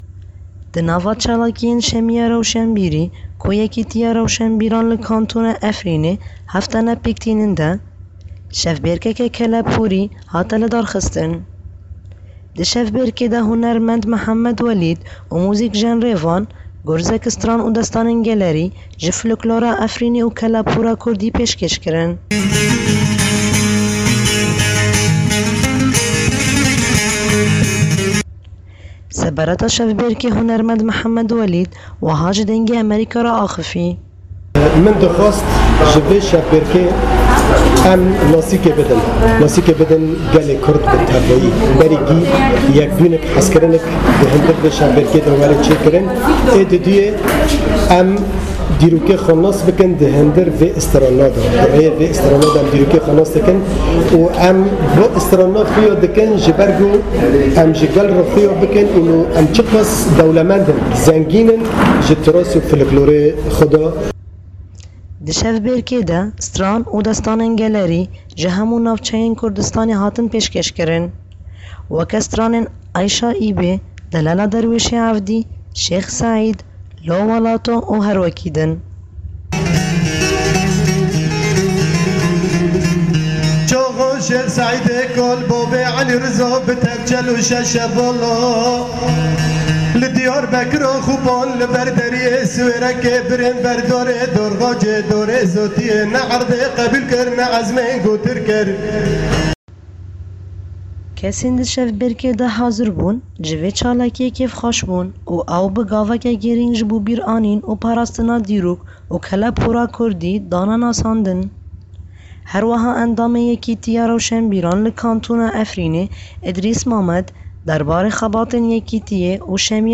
Li Kantona Efrînê, wek beşek ji çalakîyên "Şemîya Rewşenbîrî" yên heftane ku ji alîyê Yekîtîya Rewşenbîran ve tê amade kirin, şevbêrkeke keleporî hate li dar xistin.